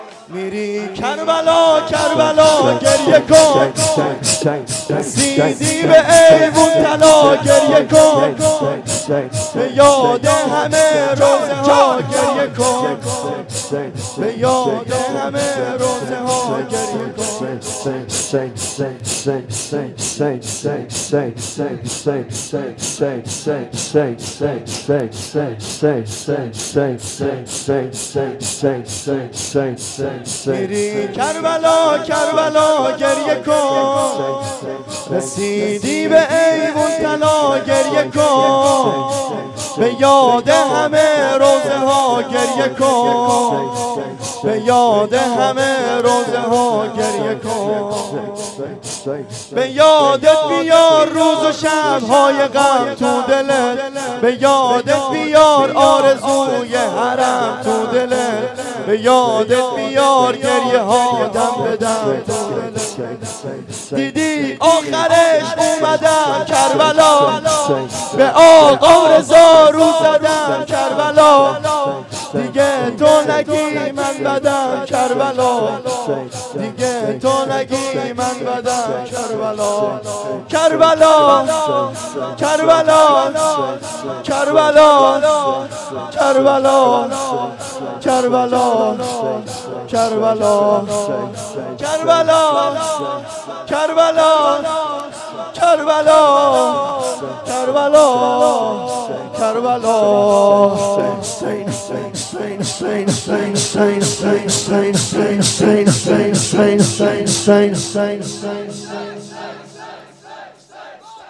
شور2